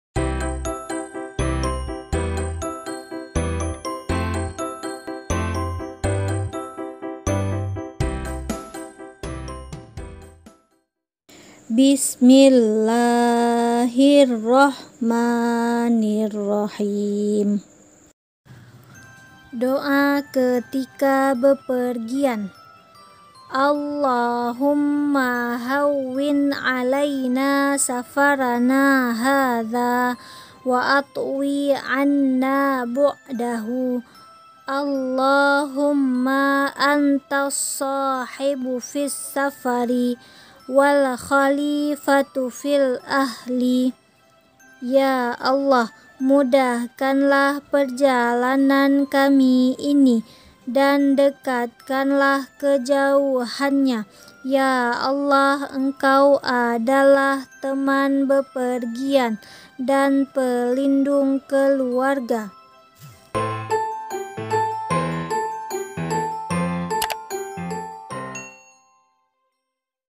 Bacaan Doa Berpergian